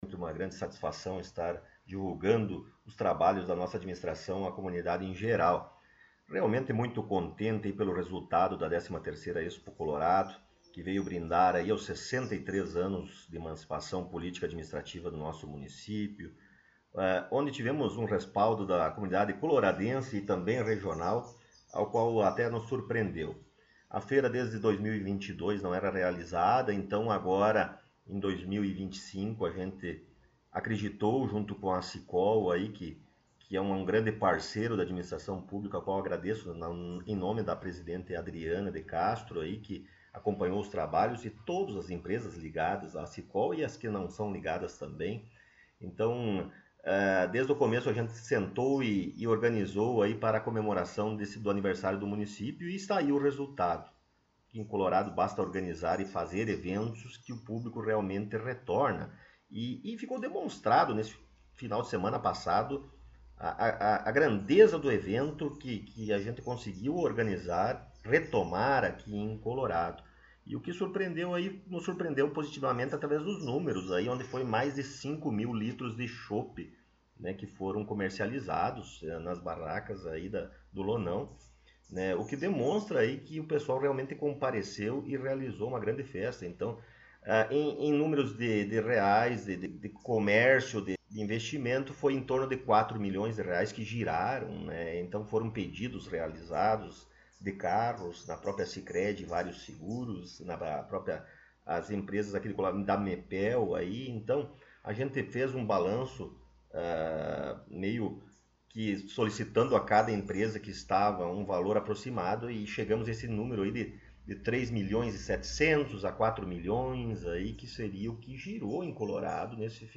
Após algumas semanas de espera, nossa equipe teve a oportunidade de entrevistar o prefeito Rodrigo Sartori em seu gabinete na Prefeitura Municipal.